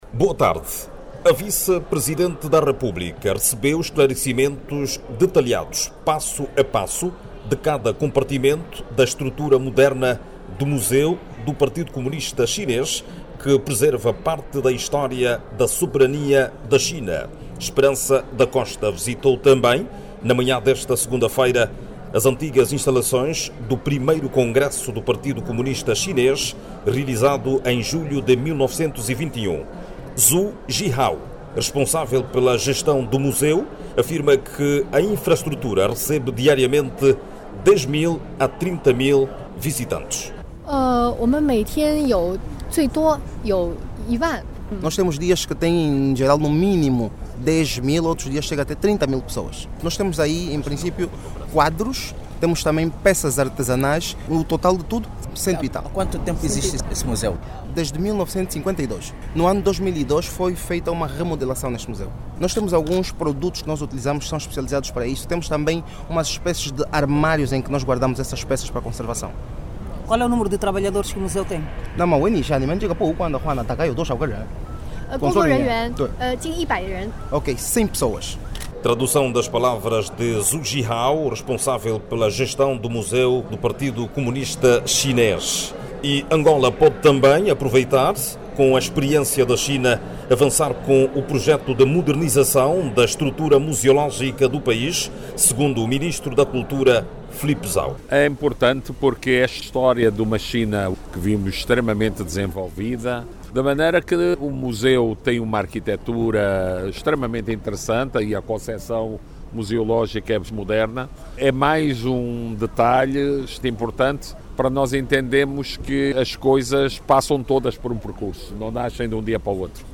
A partir de Shangai China, a reportagem com o Jornalista